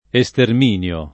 esterminio [ e S term & n L o ]